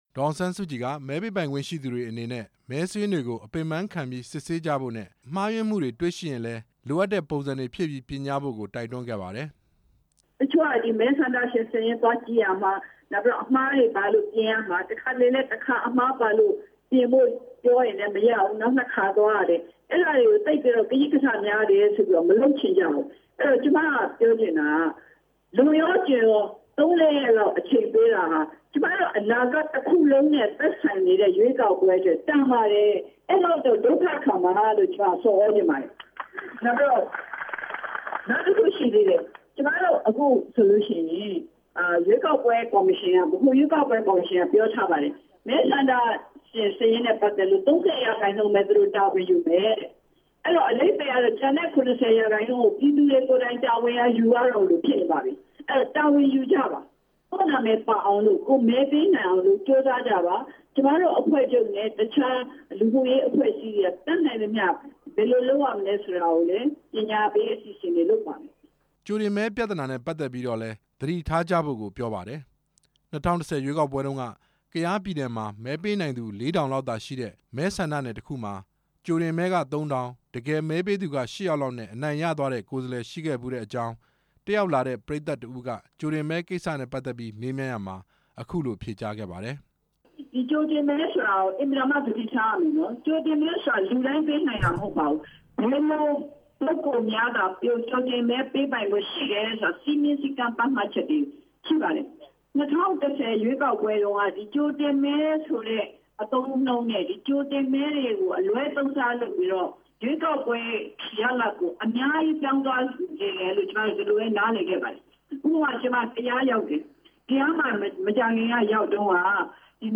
စစ်ကိုင်းတိုင်းဒေသကြီး ဟုမ္မလင်းမြို့မှာ ဒီကနေ့မနက် ရွေးကောက်ပွဲဆိုင်ရာ အသိပညာပေး ဟောပြောပွဲကျင်းပ ရာမှာ အဲ့ဒီနယ်မှာ တာဝန်ထမ်းဆောင်နေတဲ့ ဝန်ထမ်းတစ်ဦးက မဲပေးတာနဲ့ပတ်သက်ပြီး မေးမြန်းရာ မှာ ဒေါ်အောင်ဆန်းစုကြည်က ပြောခဲ့တာဖြစ်ပါတယ်။